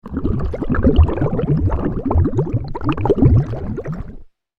دانلود آهنگ تانک 8 از افکت صوتی حمل و نقل
دانلود صدای تانک 8 از ساعد نیوز با لینک مستقیم و کیفیت بالا
جلوه های صوتی